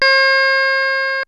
HOHNER 1982 5.wav